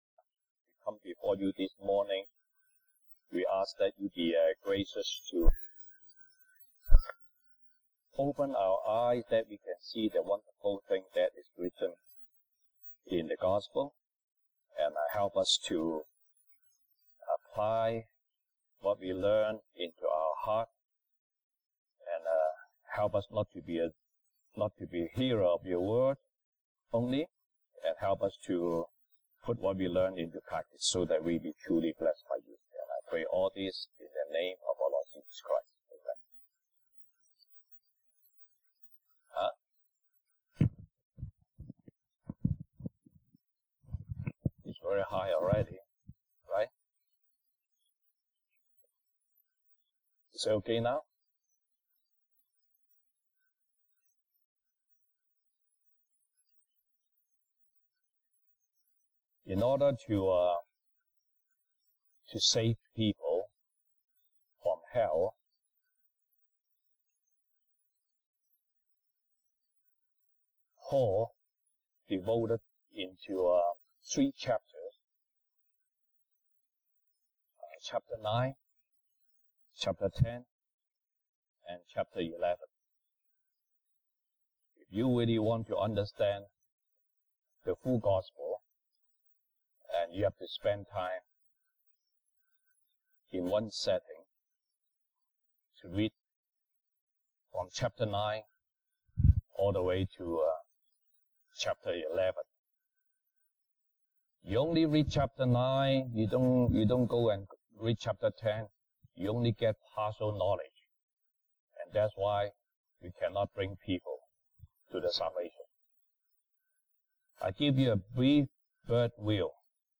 西堂證道 (英語) Sunday Service English: Speak the Truth in Love
Passage: :羅馬書 Romans 9:1-5 Service Type: 西堂證道 (英語) Sunday Service English